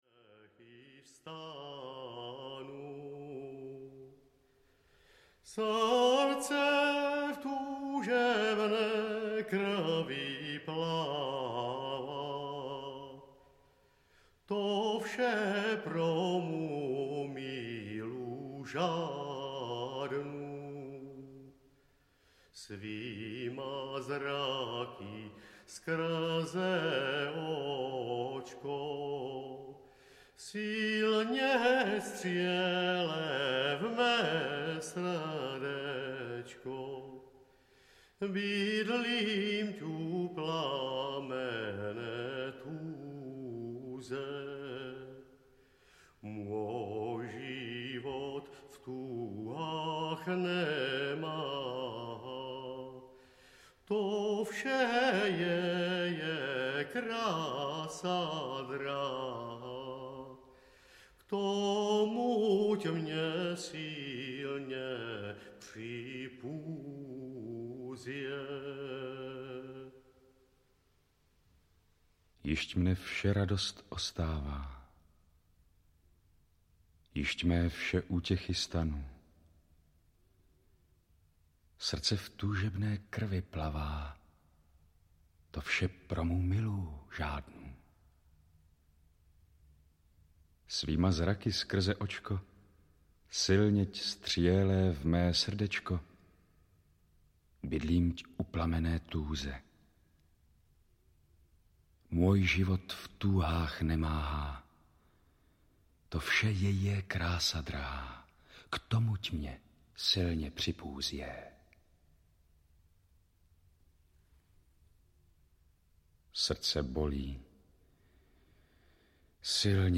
Čtveročasí básnictví českého 1 audiokniha
Ukázka z knihy
• InterpretNina Divíšková, Jiří Hanák, Petr Haničinec, Jan Kačer, Radovan Lukavský, Pavel Soukup, Václav Voska, Pavel Jurkovič